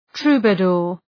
Προφορά
{‘tru:bə,dʋər}